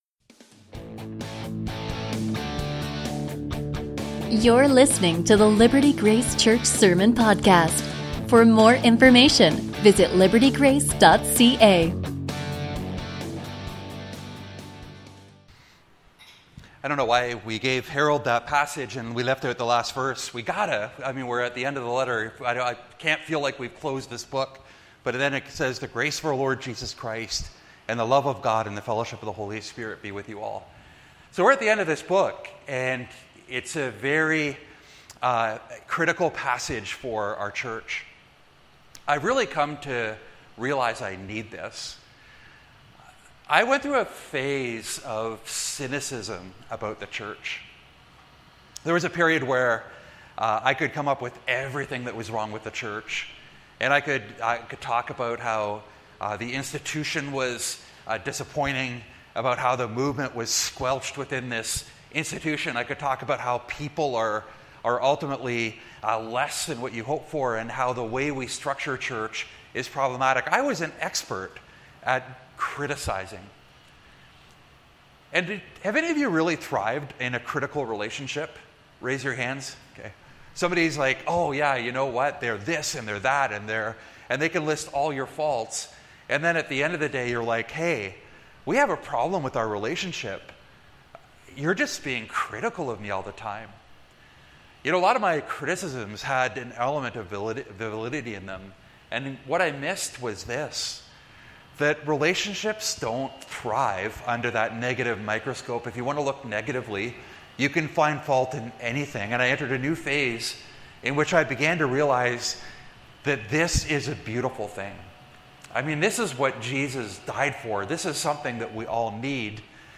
A message from the series "God of Weakness."